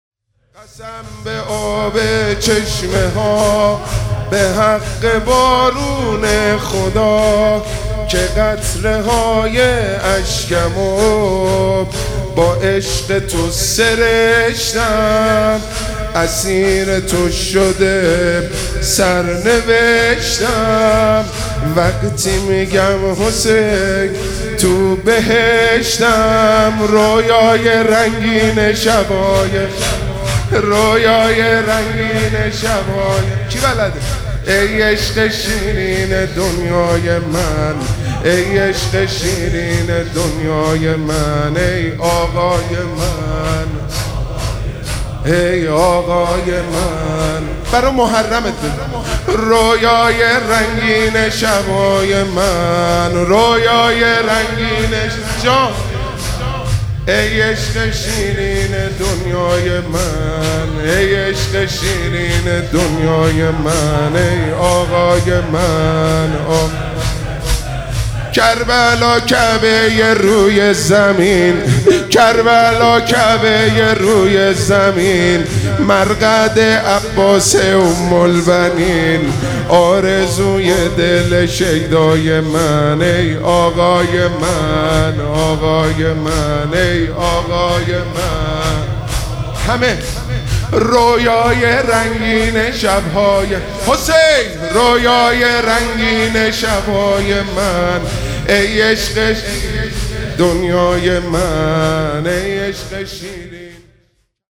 شهادت امام صادق علیه السلام
شور
مداح
شور دوم.mp3